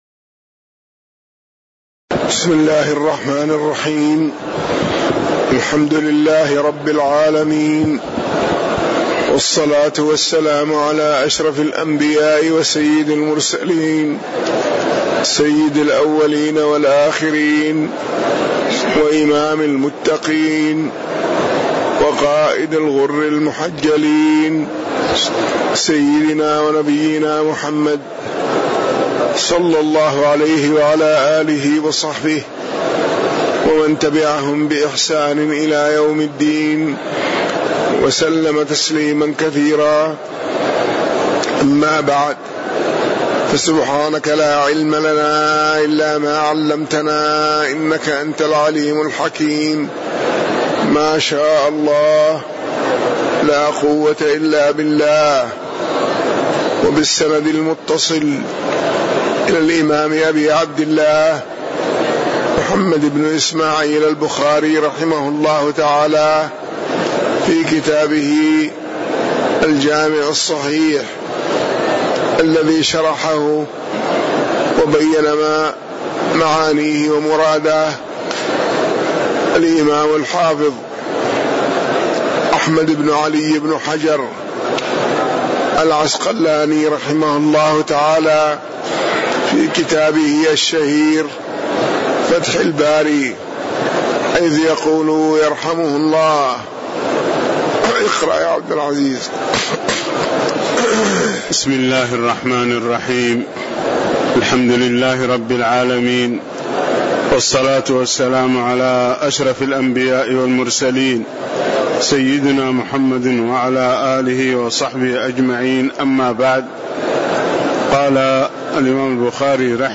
تاريخ النشر ٢٦ صفر ١٤٣٩ هـ المكان: المسجد النبوي الشيخ